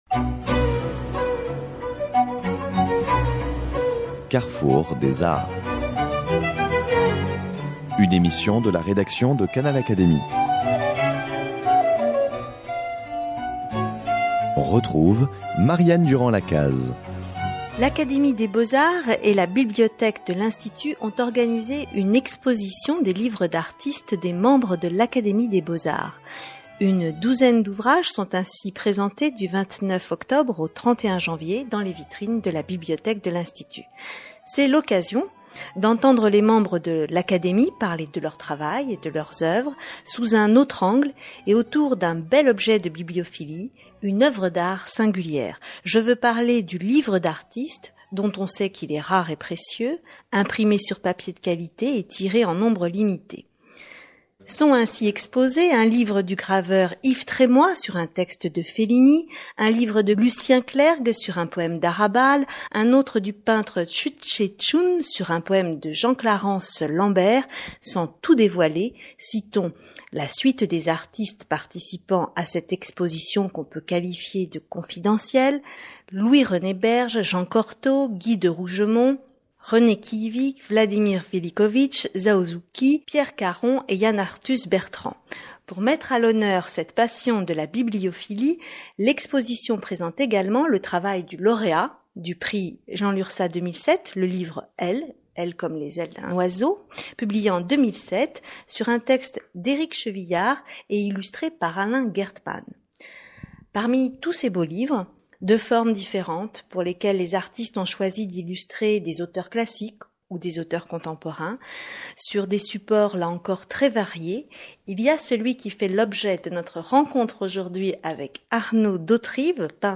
Arnaud d'Hauterives raconte l'expérience de son premier travail d'illustration d'un ouvrage, durant les psychédéliques années soixante-dix.